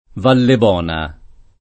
[ valleb 0 na ]